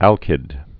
(ălkĭd)